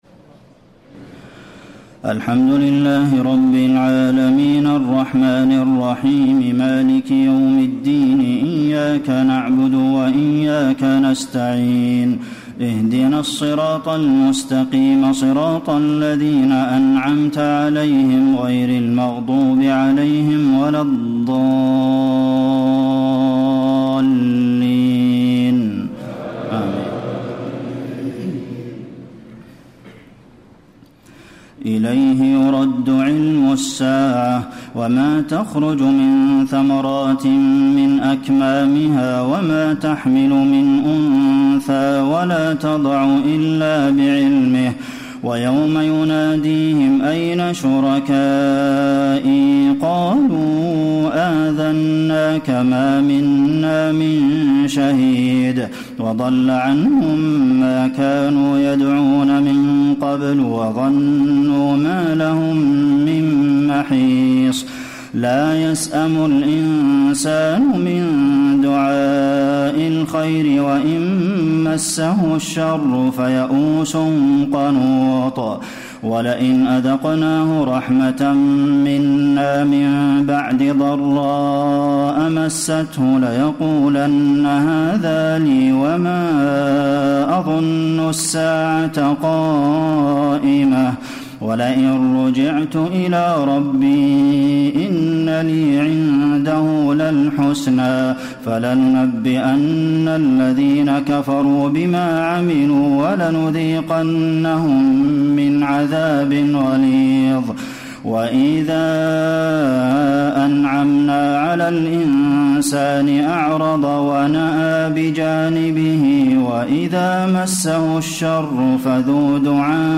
تراويح ليلة 24 رمضان 1434هـ من سور فصلت (47-54) و الشورى و الزخرف (1-25) Taraweeh 24 st night Ramadan 1434H from Surah Fussilat and Ash-Shura and Az-Zukhruf > تراويح الحرم النبوي عام 1434 🕌 > التراويح - تلاوات الحرمين